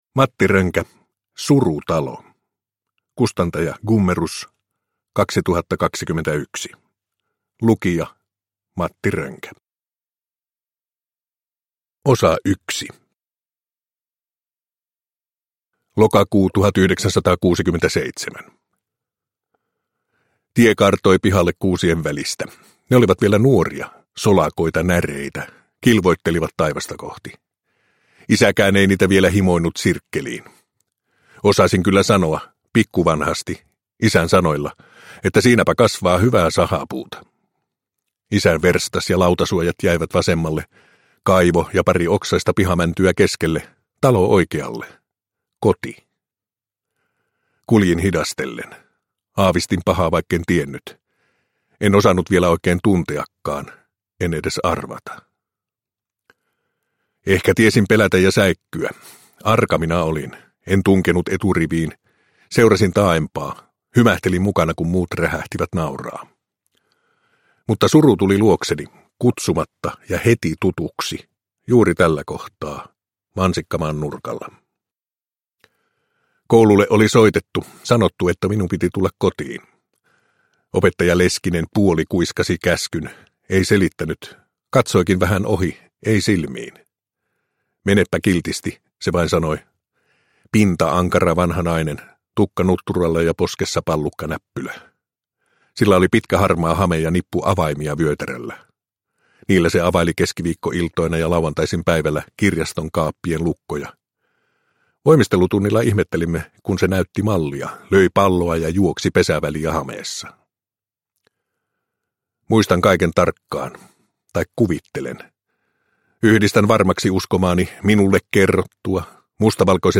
Surutalo – Ljudbok – Laddas ner
Uppläsare: Matti Rönkä